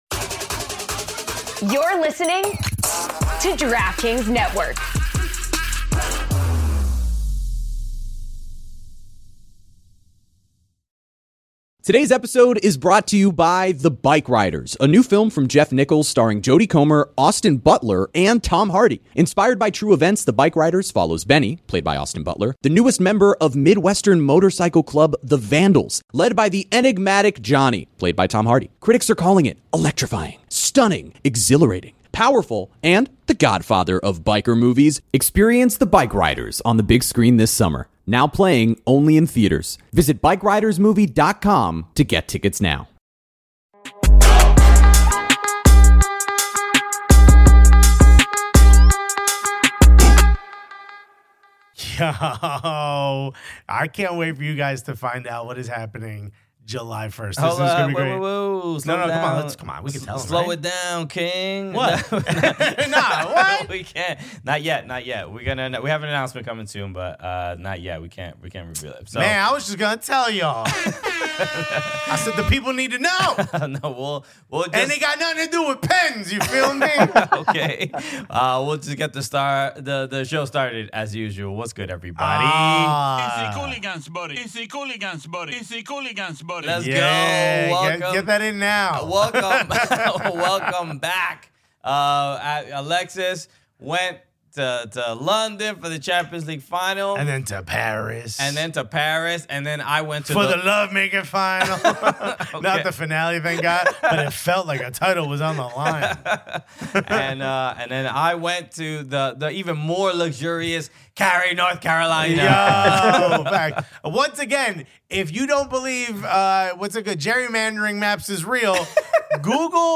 Welcome to Episode 35 of Glory Days of Gold.Recorded across two continents, Glory Days of Gold brings you a regular dose of East Fife FC and Scottish football chat, with a splattering of interesting interviews, fun segments, and music thrown in along the way.We've lots of chat and two interviews for you this week.